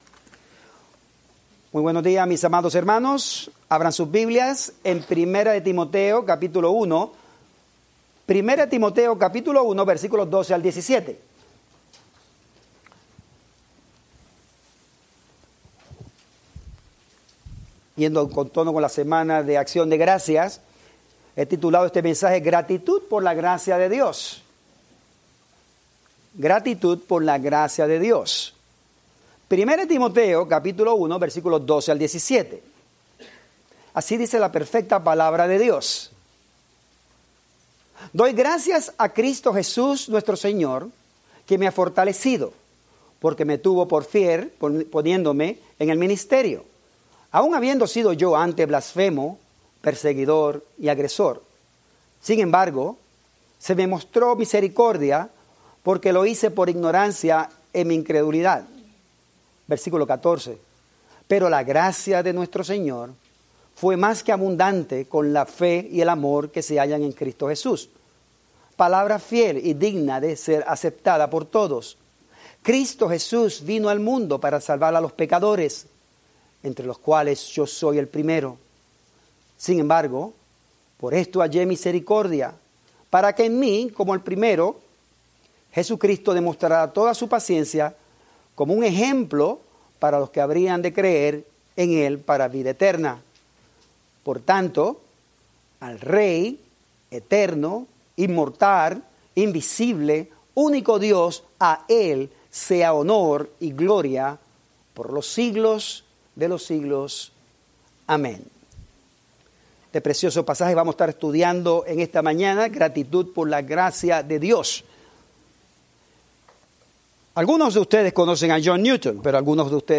Spanish Bible Study